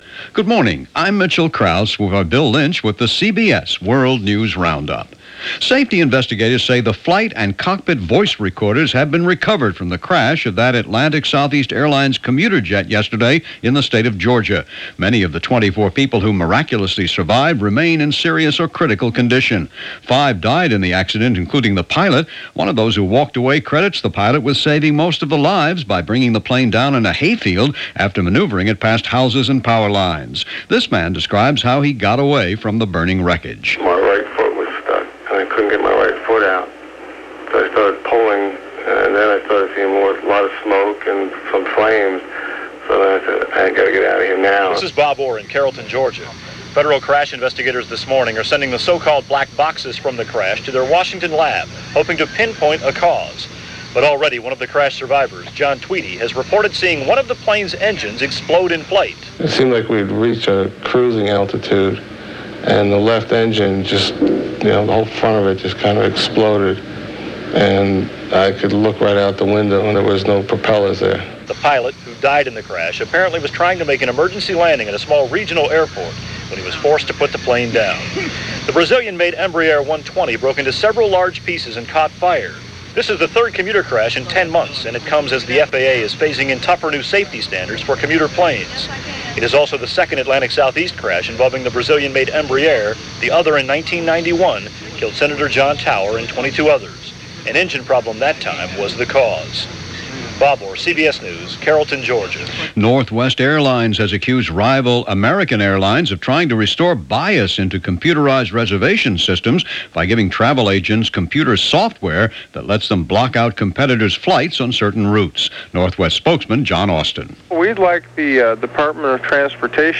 And that’s a small slice of what went on, this August 22, 1995 as reported by The CBS World News Roundup.